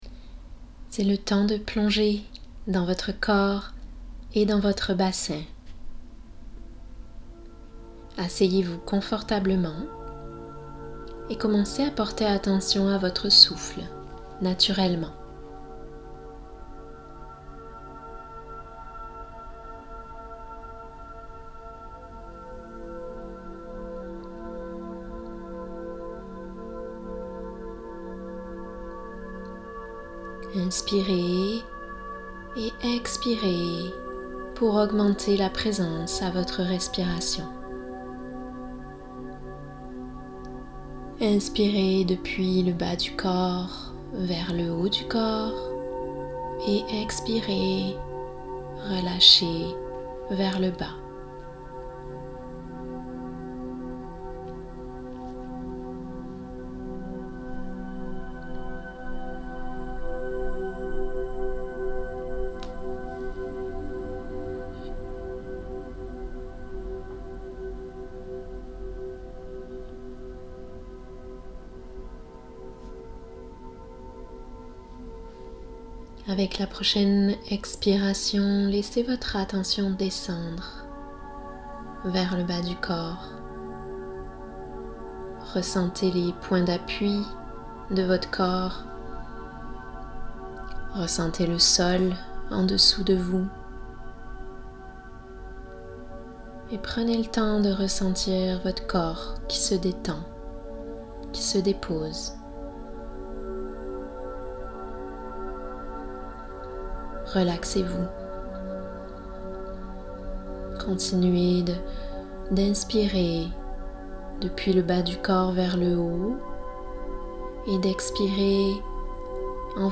************** + MÉDITATION CONNEXION À L’UTÉRUS : entrez en contact dès maintenant avec votre Ventre grâce à cette pratique guidée, écoutez-la ici.
meditationconnexionventre